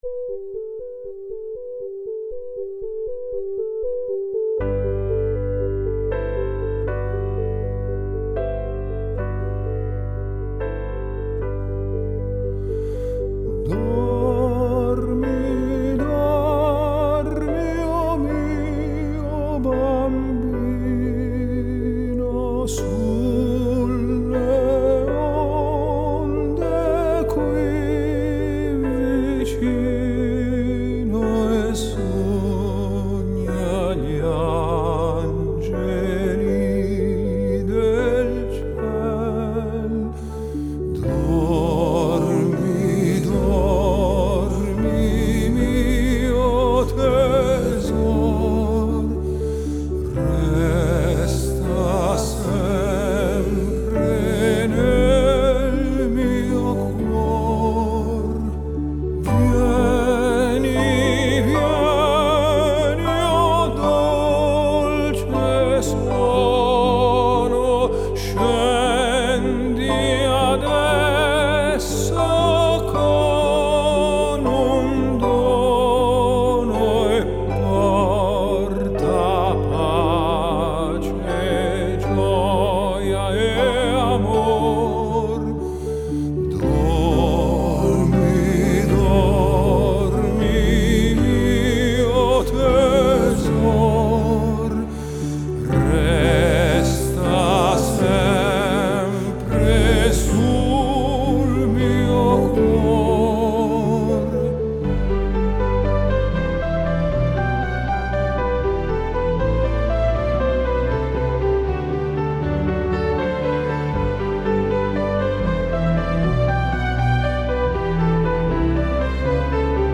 Genre: Crossover